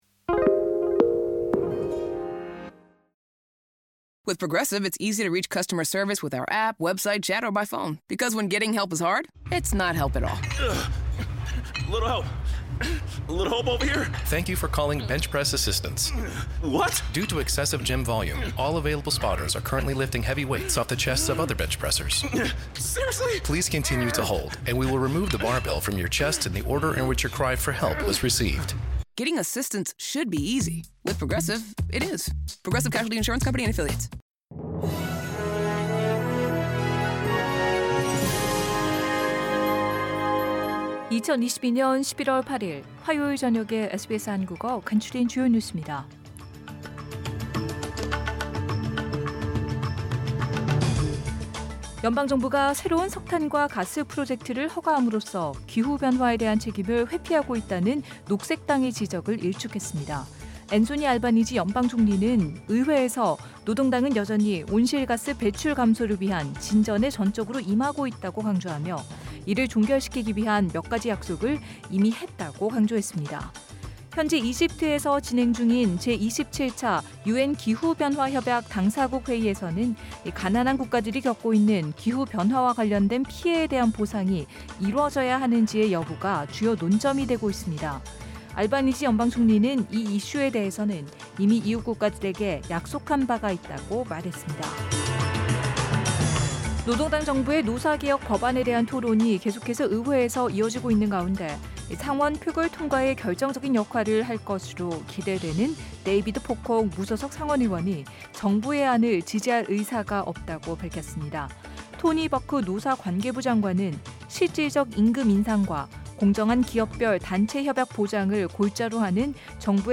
2022년 11월 8일 화요일 저녁 SBS 한국어 간추린 주요 뉴스입니다.